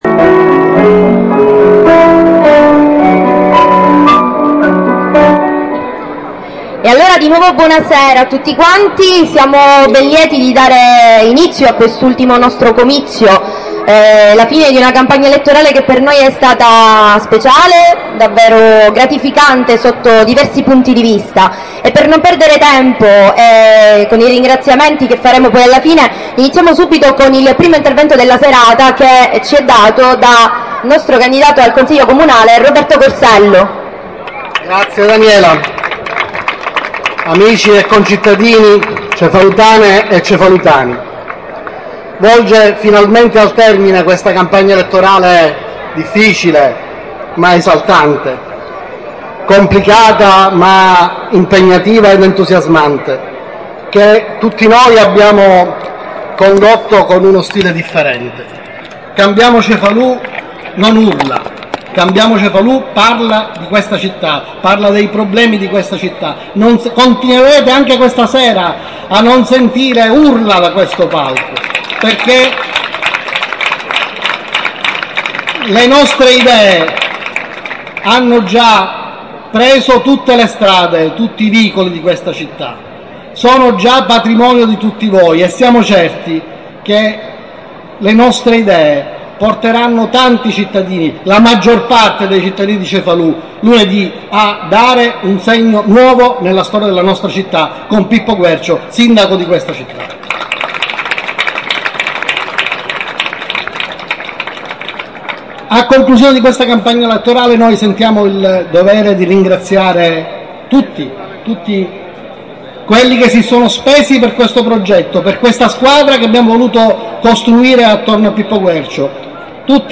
Ultimo comizio lista "CambiAMO Cefalù"